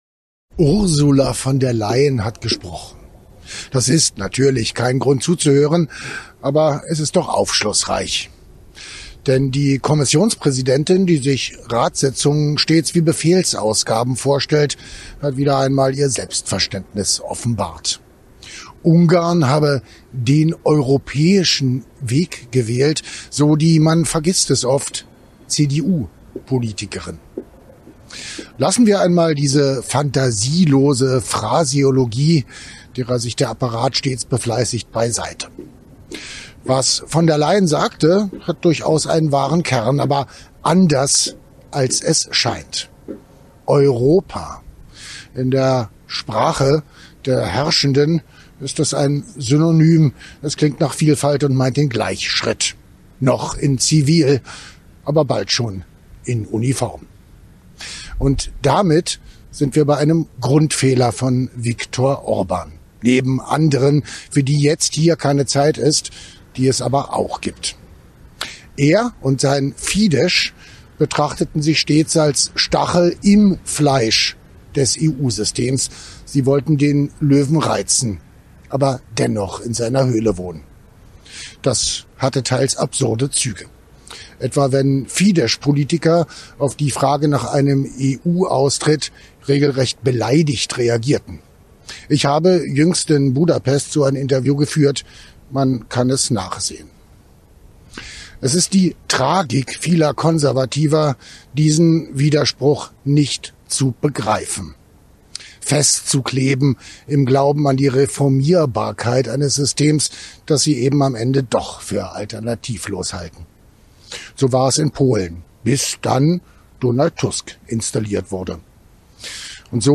Kommentar: Verpasste Chance – Ungarn hätte die EU verlassen sollen